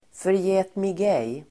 Ladda ner uttalet
Folkets service: förgätmigej förgätmigej substantiv, forget-me-not Uttal: [förjä:tmig'ej:] Böjningar: förgätmigejen, förgätmigejer Definition: liten blå blomma (Myosotis palustris) (a small blue flower (Myosotis palustris))